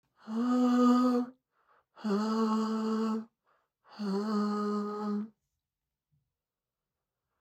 Der Chorus-Effekt macht ihre Stimme ebenfalls breiter und lässt sie breitflächig klingen.
CHORUS:
fl-studio-vocals-chorus.mp3